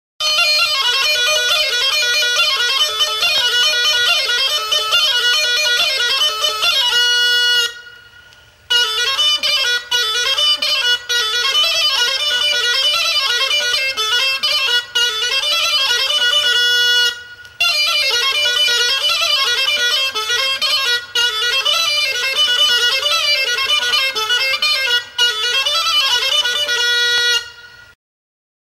Instruments de musiqueXEREMIA; RECLAM DE XEREMIA
Aérophones -> Anches -> Simple battante (clarinette)
RECLAM DE XEREMIA
Klarinete sinple txikia da.